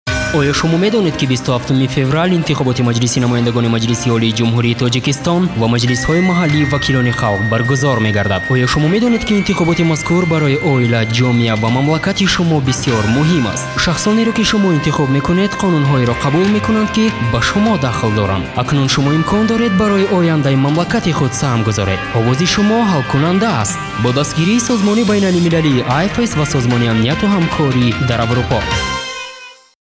A thirty-second voter information radio message